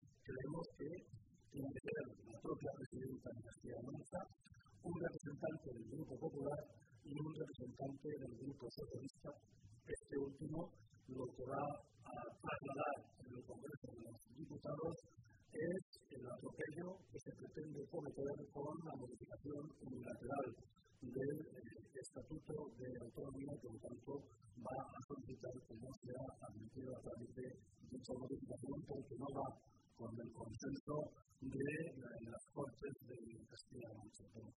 José Luís Martínez Guijarro, portavoz del Grupo Parlamentario Socialista
Cortes de audio de la rueda de prensa